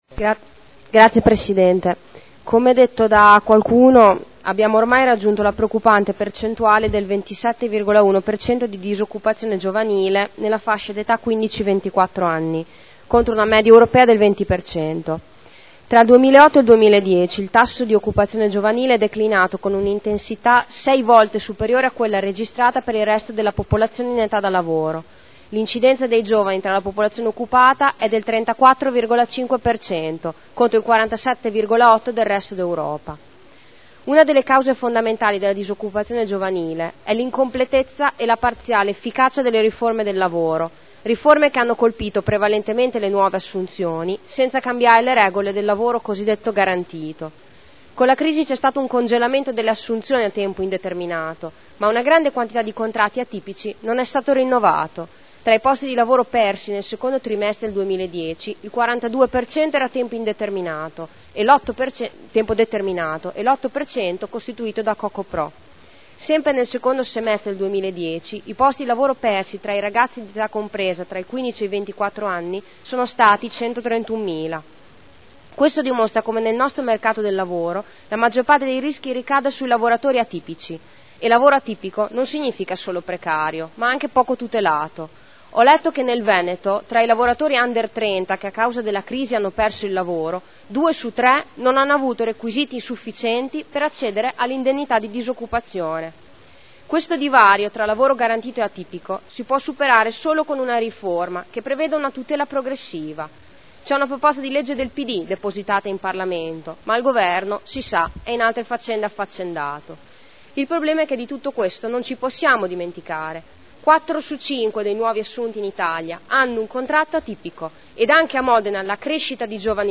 Giulia Morini — Sito Audio Consiglio Comunale